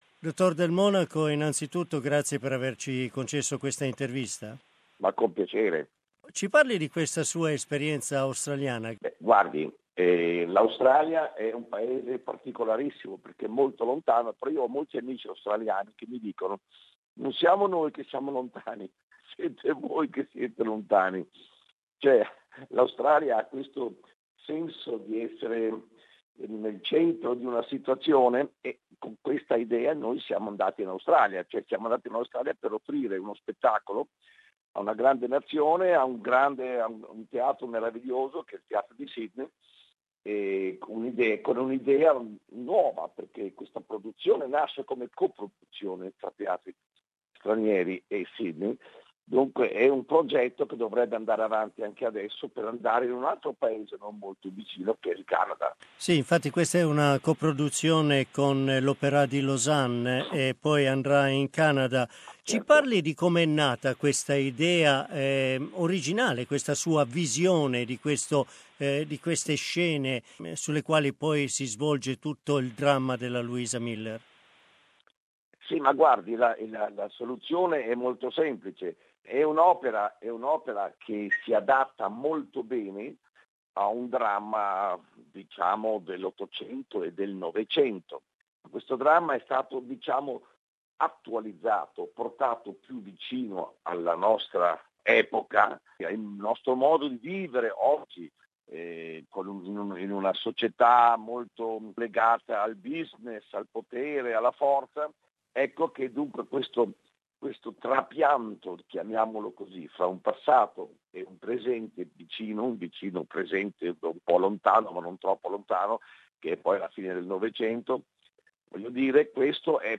In his long activity which began over 50 years ago, Del Monaco has directed operas in the world's biggest theatres. In our interview he talks about his Australian experience.